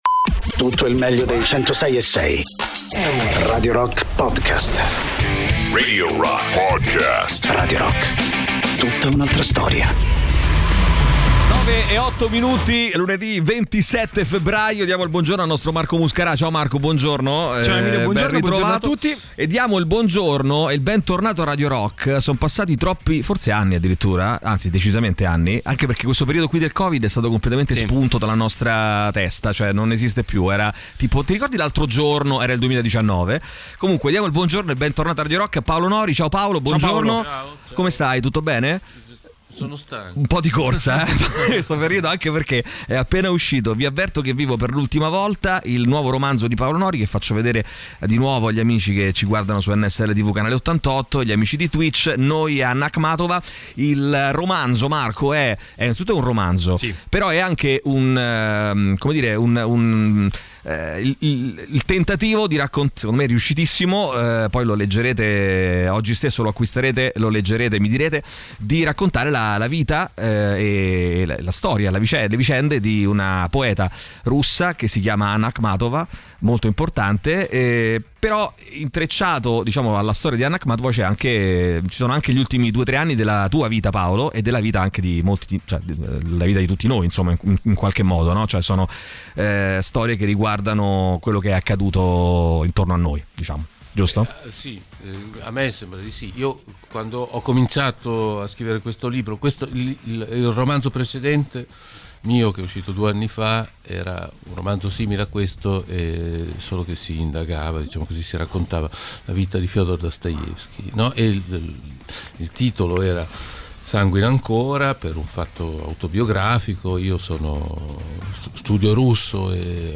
Interviste: Paolo Nori (27-02-23)